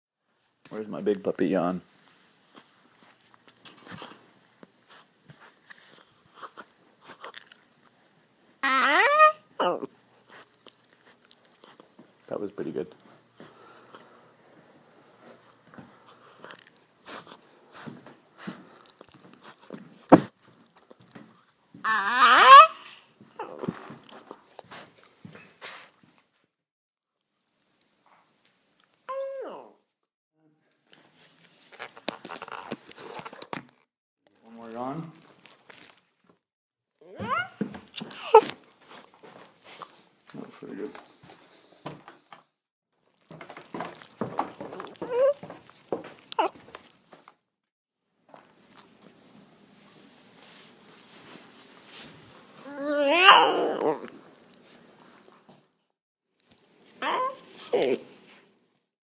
Puppy Yawns (for your Listening Pleasure!)
These yawns are compiled from two of the early morning (2:45am and 5:15am to be exact!) “take her out to pee!” runs (iPhone Voice Memo app FTW!)